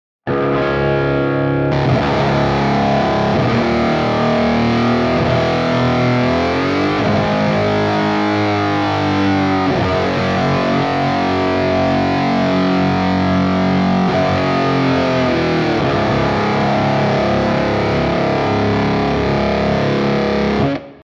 Overemphasize (just fuzz)
Overemphasize.wav-just-fuzz.mp3